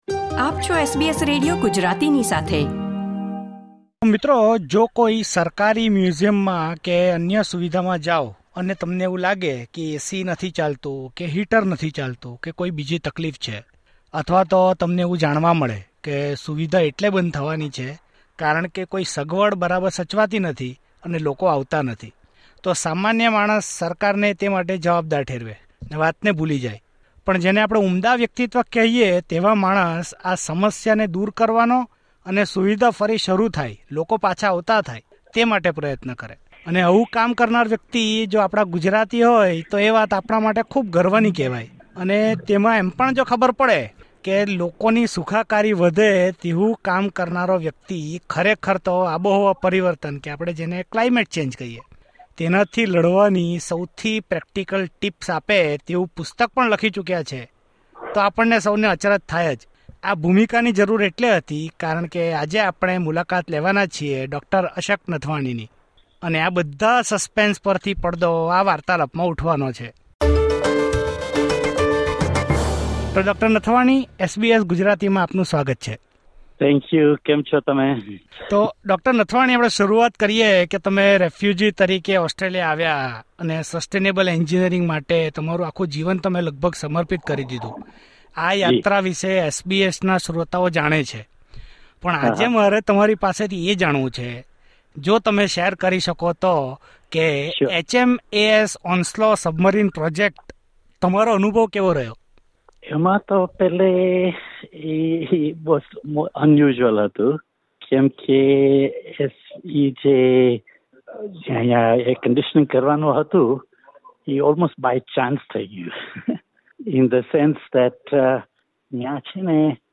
at SBS Sydney Studio Source